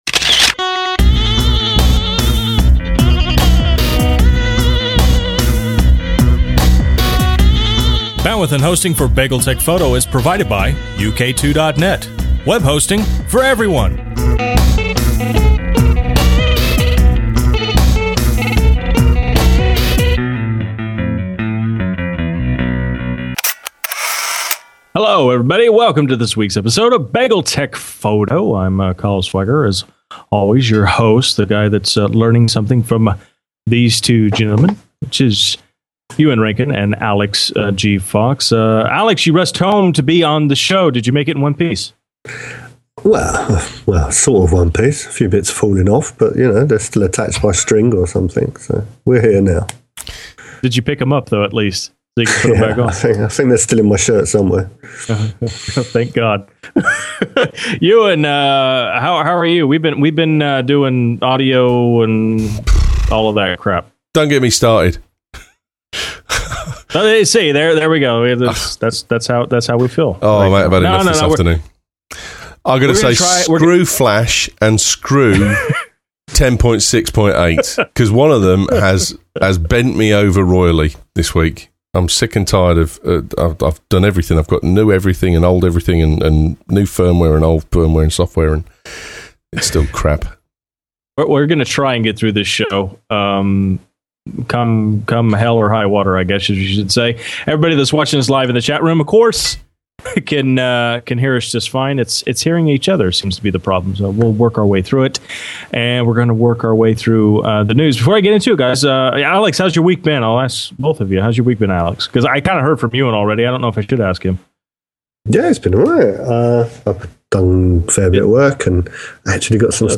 Through some dreadful audio issues this weekend, we try and bring you a normal show, sorry for any deficiencies